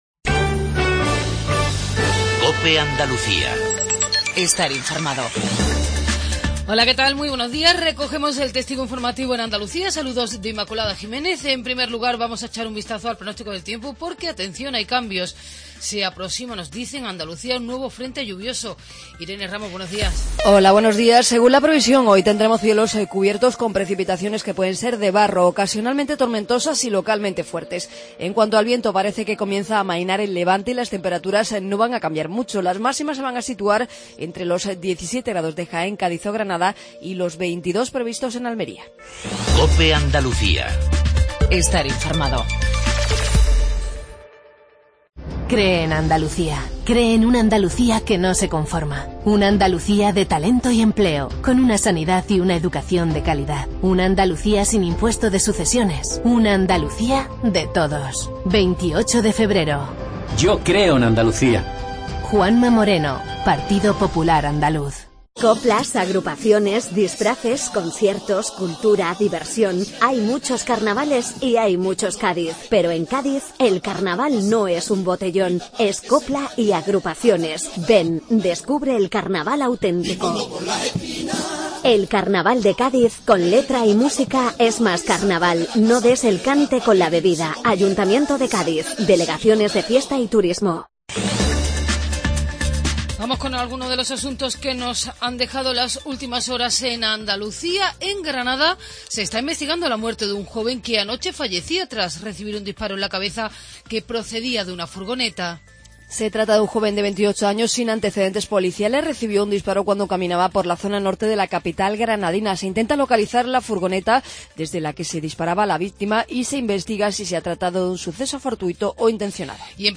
INFORMATIVO REGIONAL MATINAL 7:20